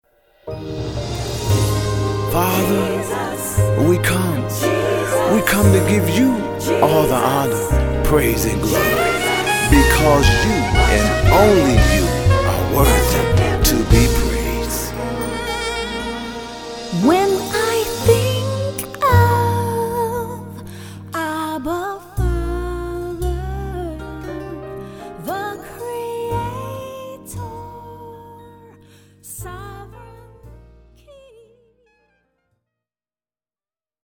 (Vocal)
Gospel jazz